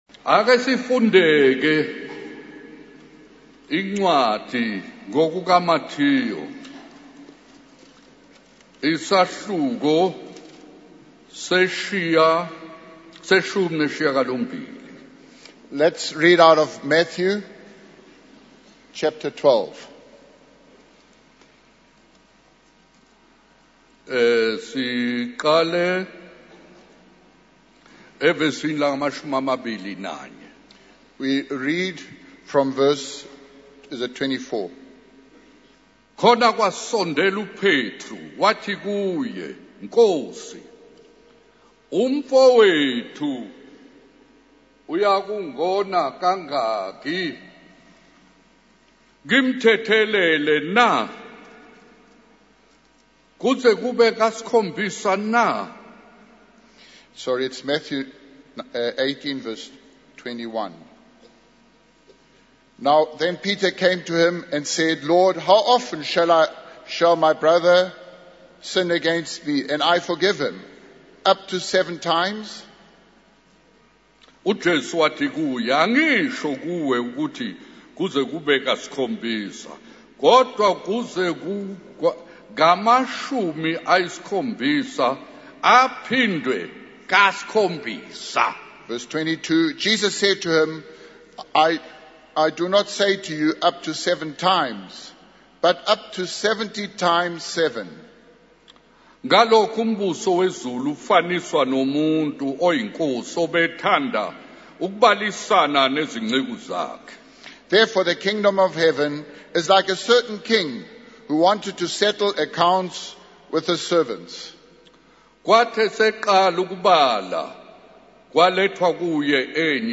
In this sermon, the preacher emphasizes the importance of forgiveness and how it is a characteristic of the kingdom of God. He uses the parable of a king settling accounts with his servants to illustrate this point.